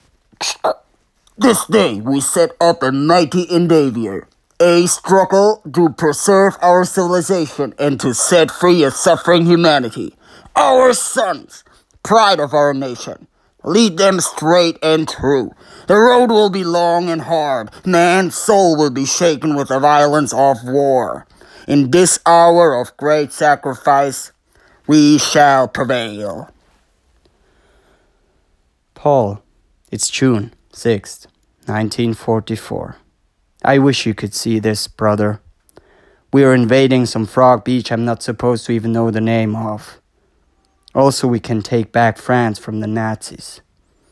VOICE
Voice: Clear
Voice Character: Engaging